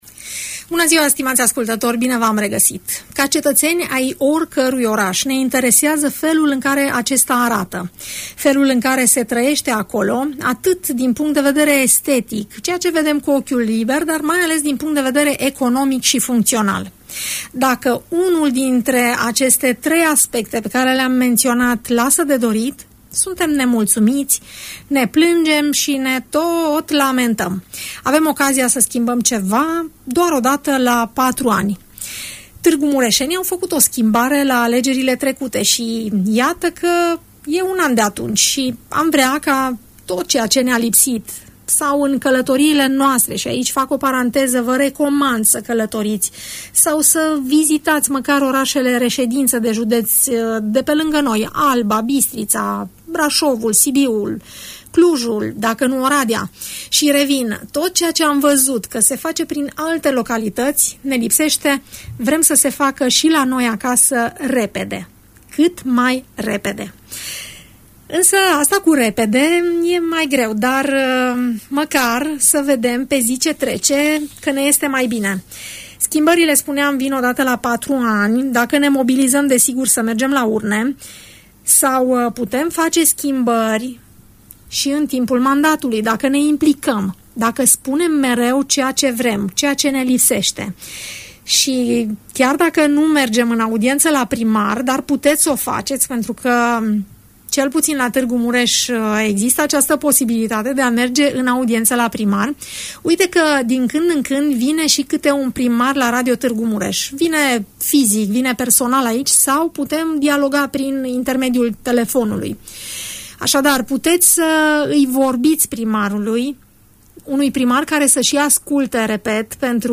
Primarul de Tg Mureș, dl Soós Zoltán, în direct la RTM